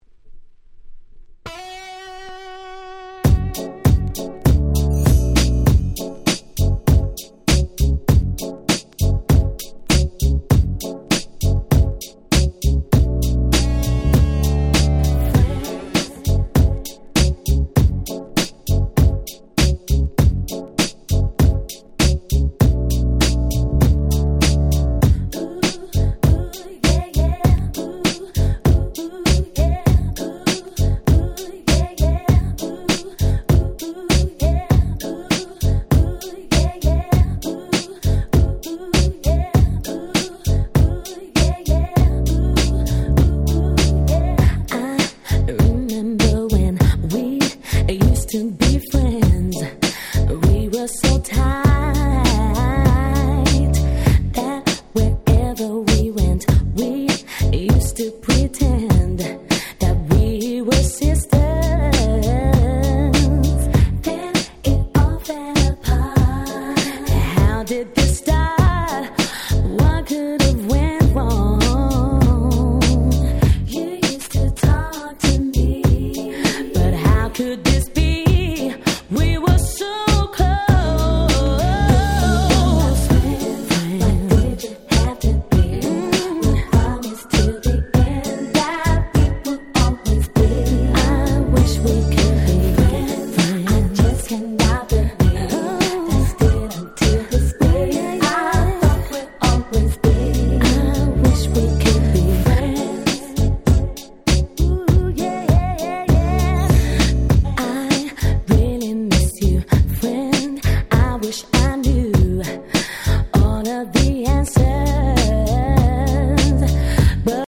00' Very Nice UK R&B !!
キャッチー系 ウエストコースト West Coast